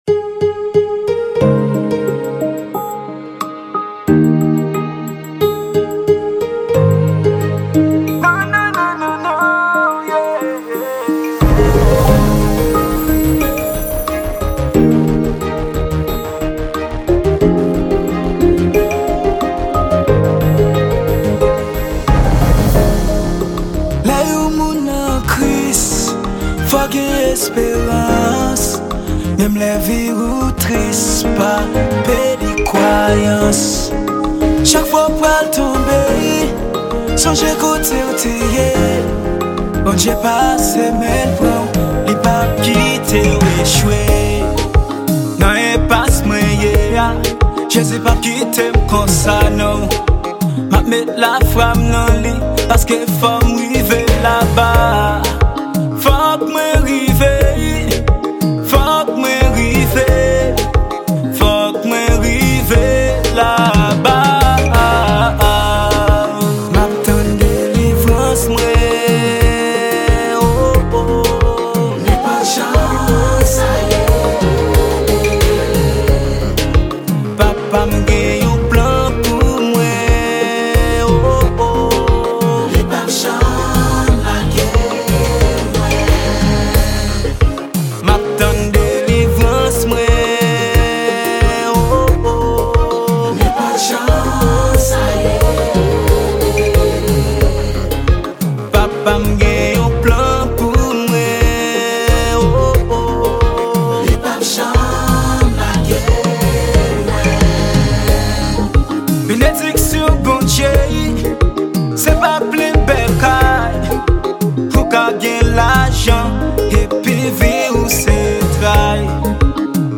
Genre : GOSPEL